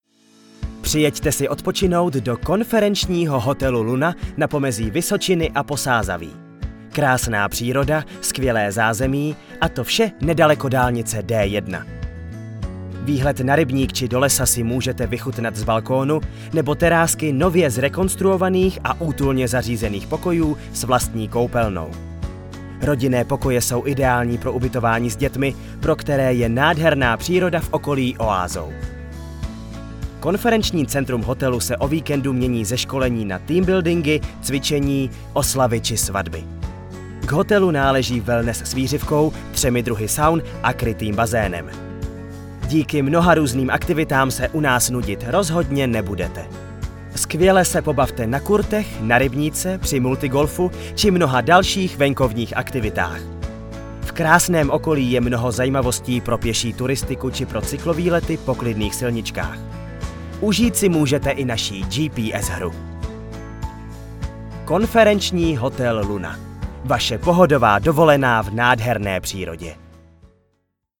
Profesionální voice-over pro vaše video
• samotné studiová nahrávka a případný mix dodaného hudebního podkladu.